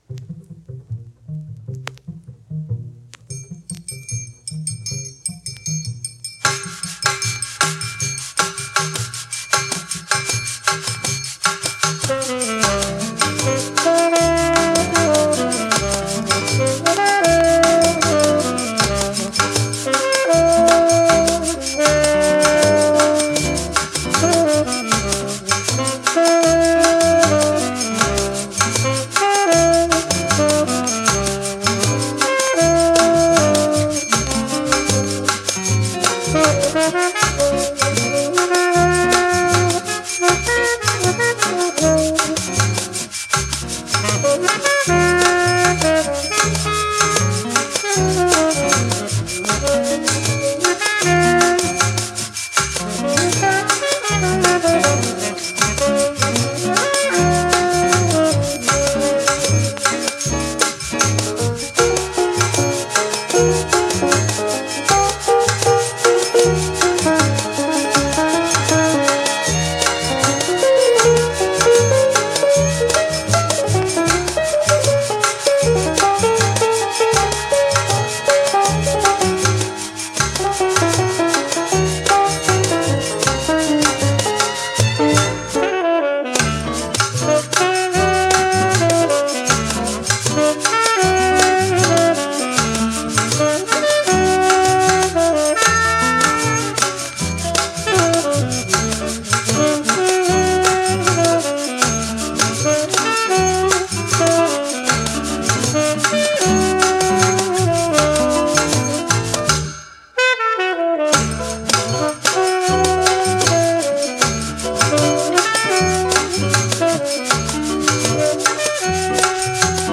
Son: mono / stéréo